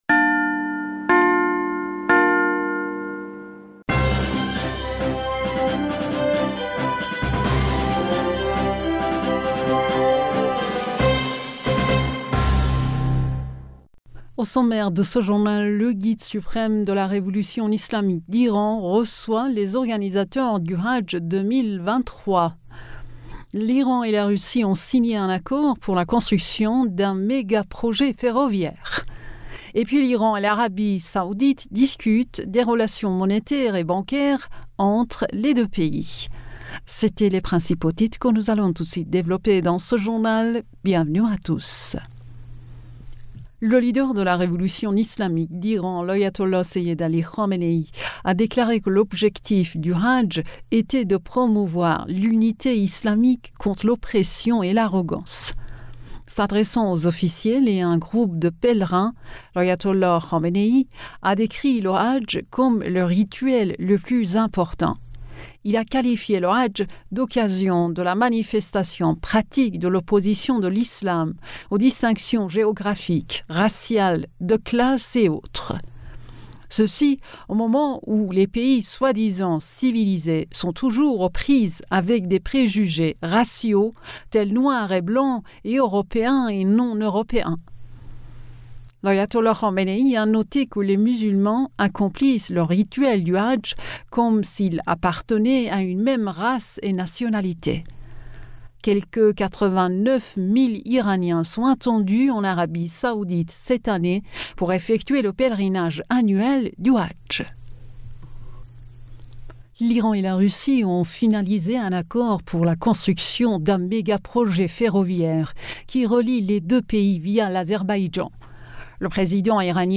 Bulletin d'information du 17 Mai 2023